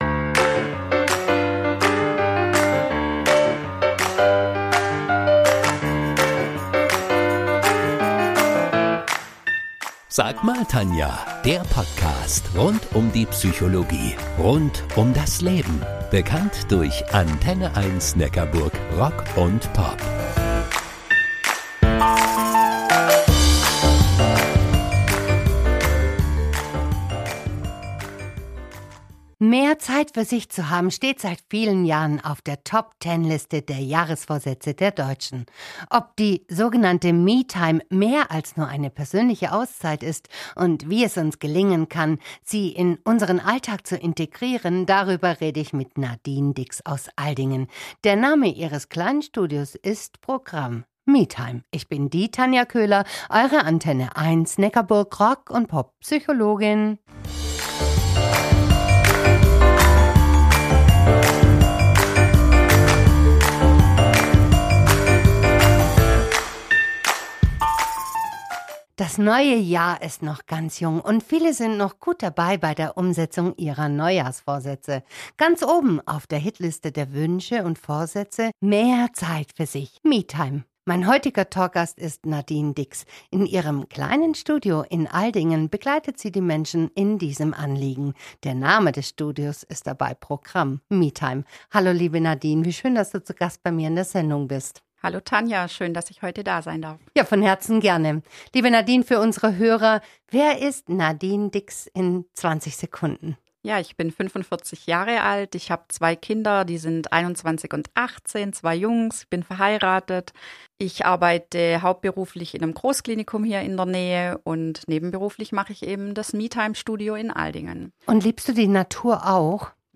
Im Gespräch